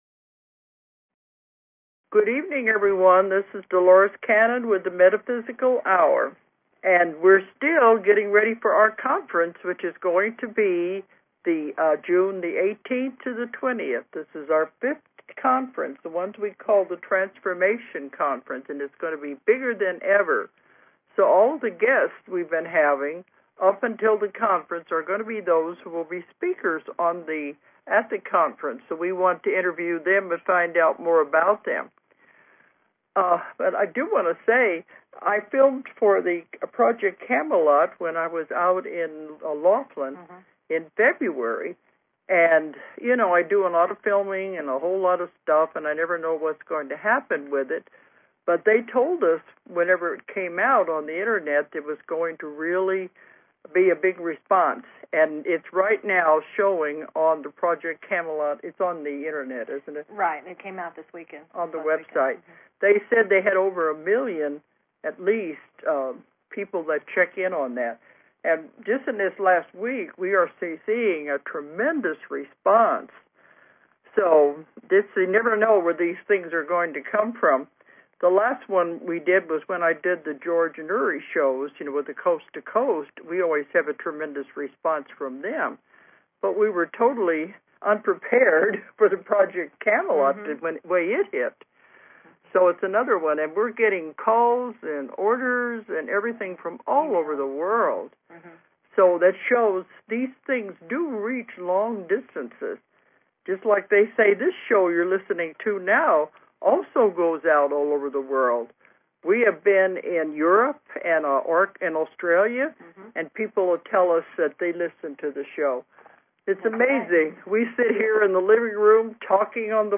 The Metaphysical Hour Talk Show